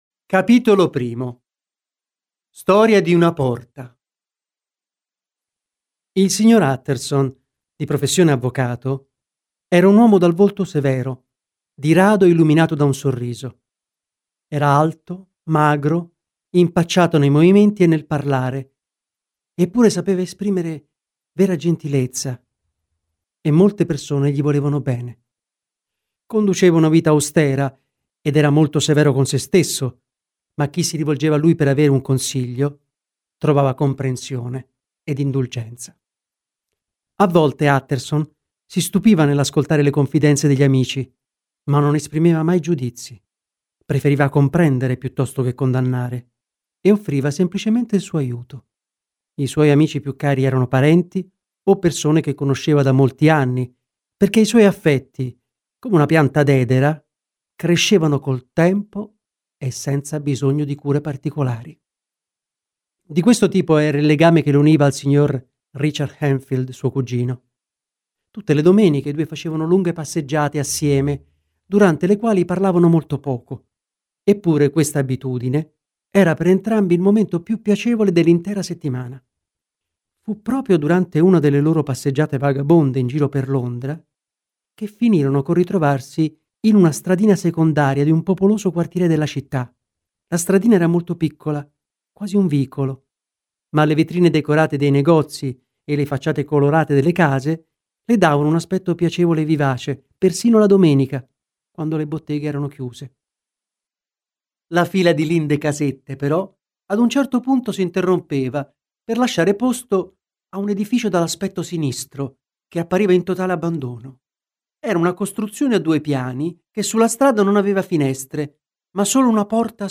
Letto da: Giulio Scarpati